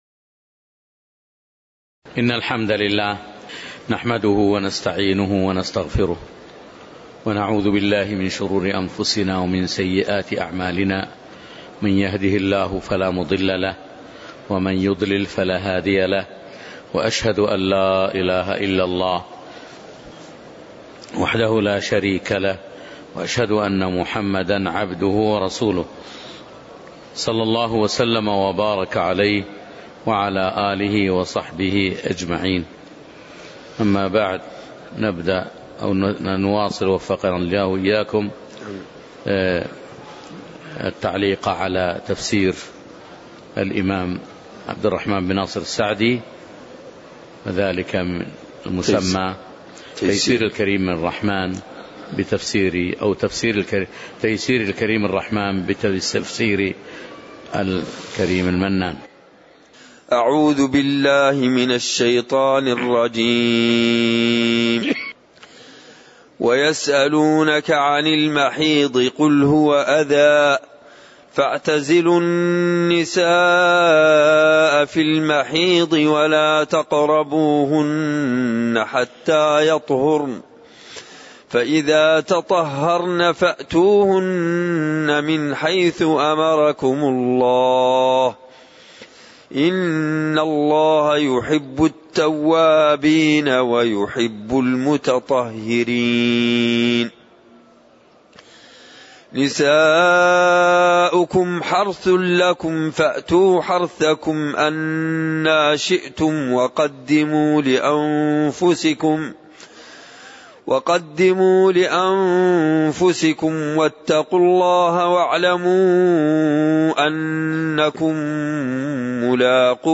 تاريخ النشر ١٤ صفر ١٤٣٩ هـ المكان: المسجد النبوي الشيخ